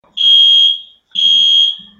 Fire Alarm Short